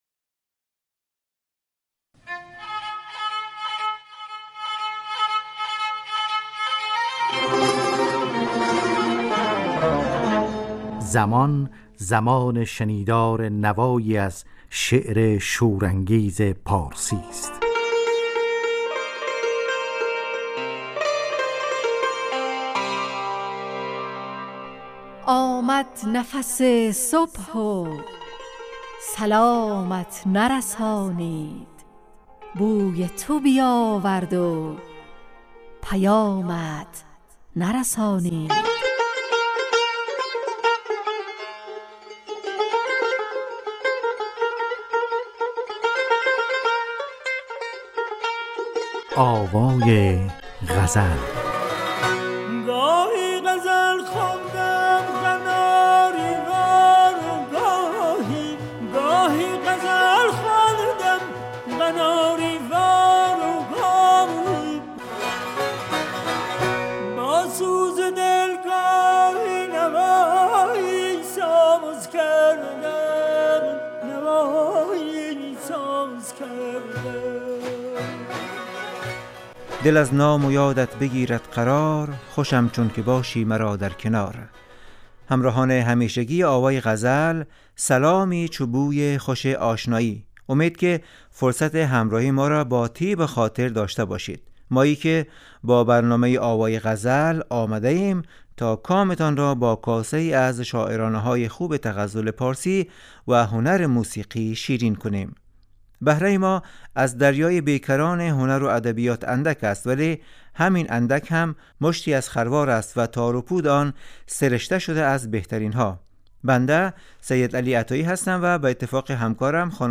آوای غزل نواهنگ رادیویی در جهت پاسداشت زبان و ادبیات فارسی . خوانش یک غزل فاخر از شاعران پارسی گوی و پخش تصنیف زیبا از خوانندگان نامی پارسی زبان .